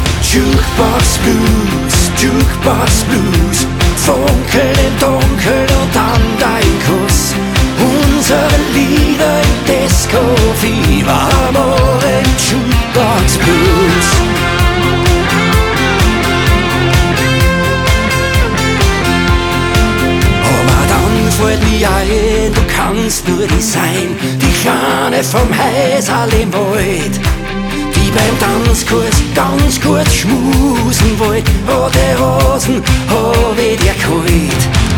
Жанр: Фолк-рок
# German Folk